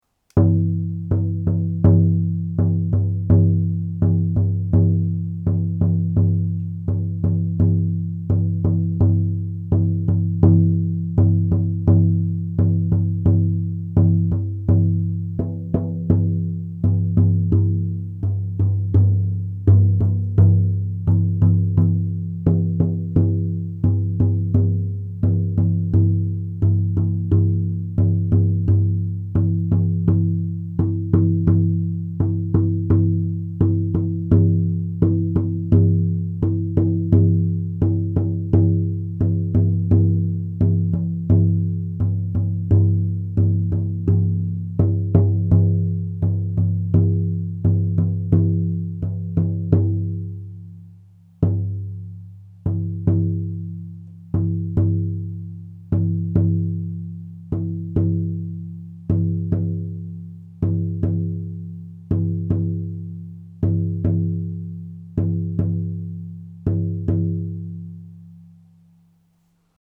16″ Shamanic Reindeer Drum
Reindeer Hide laced on a beeswaxed 16″ Ash frame and a turquoise leather bound ring.
This striking shamanic reindeer drum has a powerful tone to its song, deeply resonant it would make a wonderful healing or journeying drum.
Listen to a recording of “Soul Transformation” here – use headphones or speakers to hear the full range of tones.